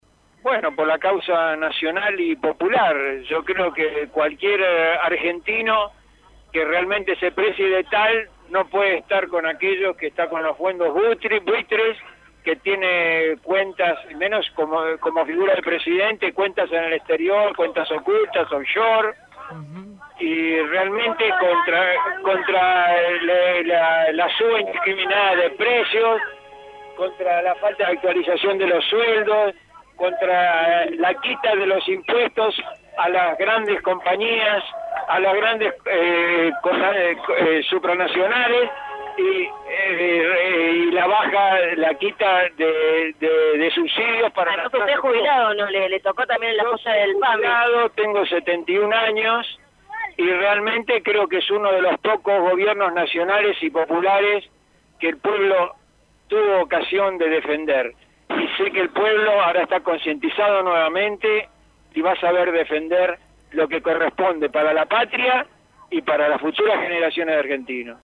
(13/4/2016) Citada a indagatoria por el juez Claudio Bonadio, en la causa de dólar futuro, esta mañana Cristina Fernández de Kirchner presentó un escrito en los Tribunales de Comodoro Py, mientras que en las afueras de la sede judicial una multitud proveniente de distintos puntos del país se congregó para brindar su apoyo a la ex mandataria.